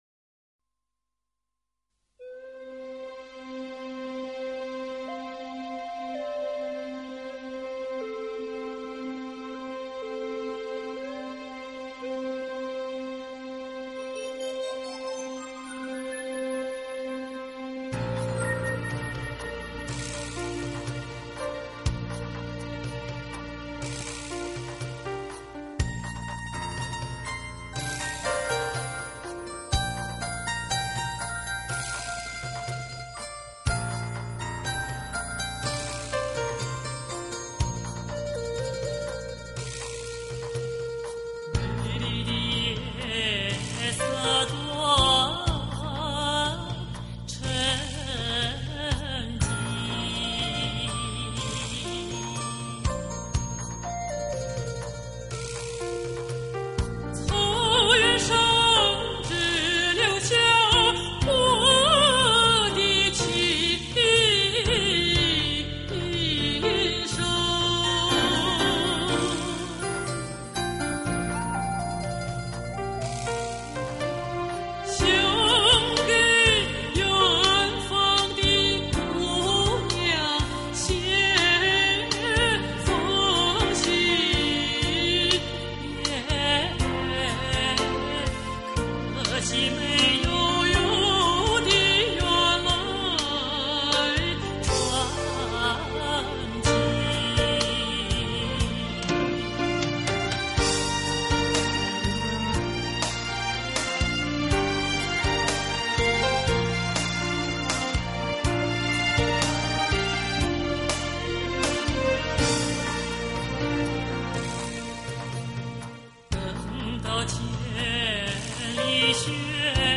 她的歌声是那样的柔美而富有磁性；
是那样的情真意切而令人陶醉；犹如天籁之音，绕梁三日。